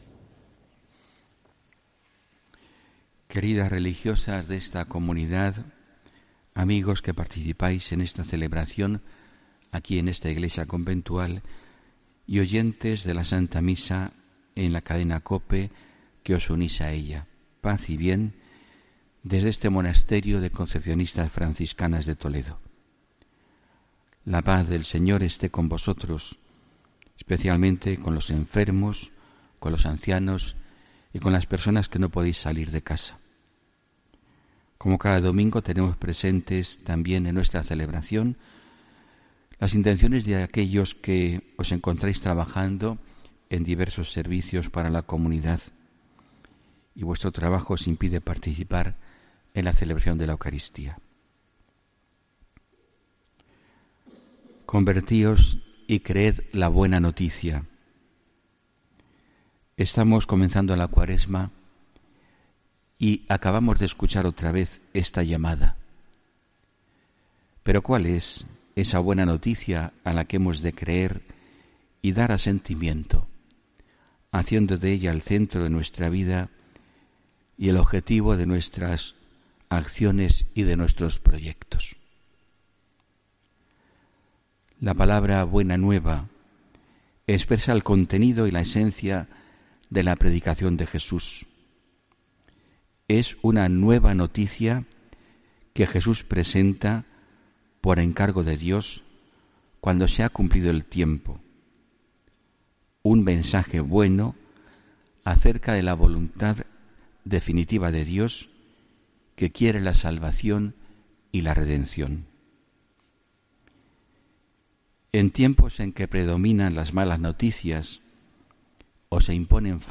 HOMILÍA 18 FEBRERO 2018